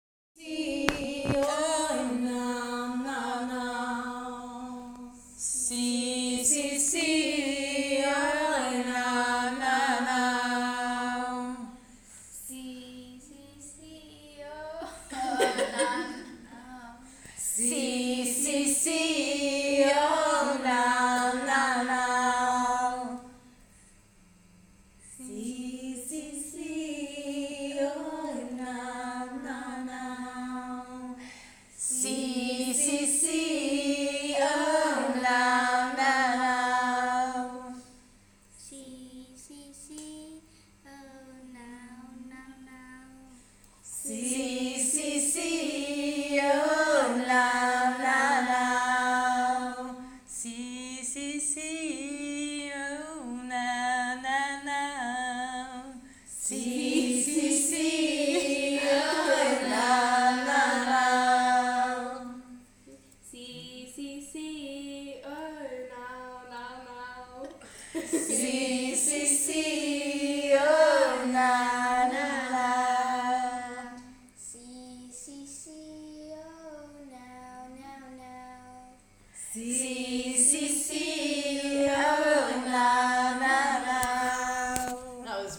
capoeira-song.m4a